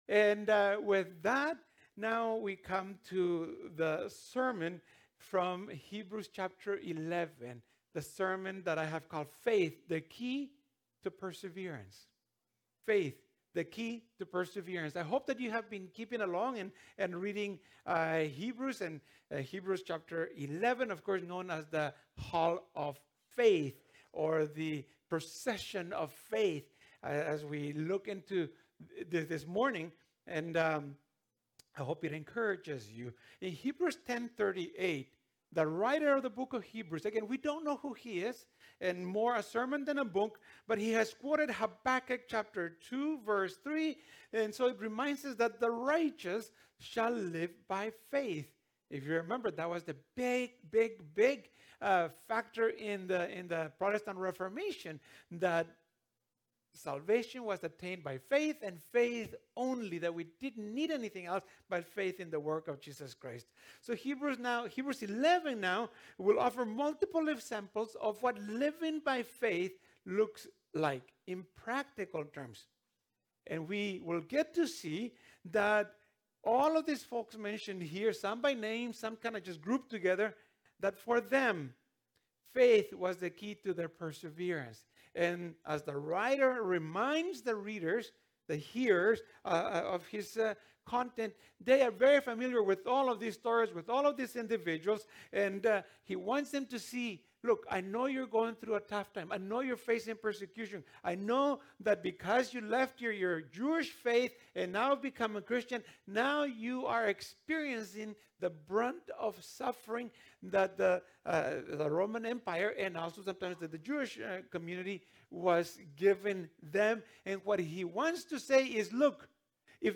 Sermons | Devon Community Church